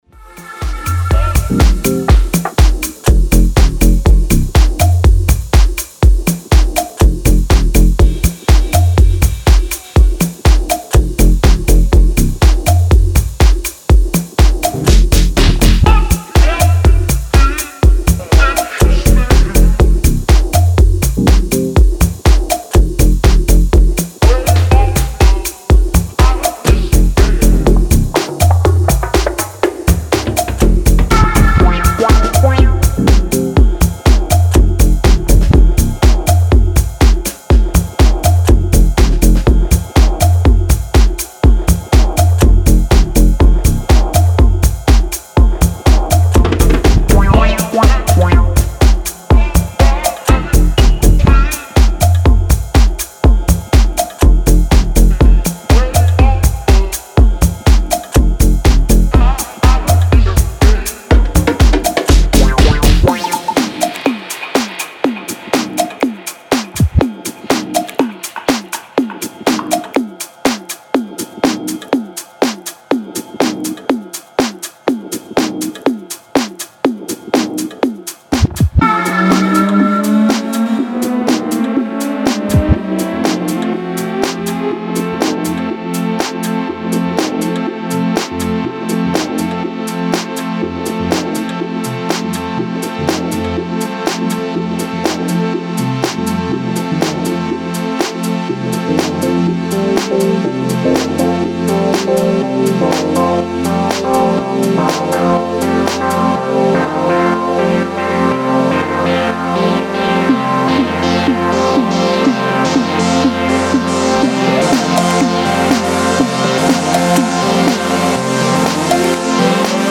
Style: Techno / Tech House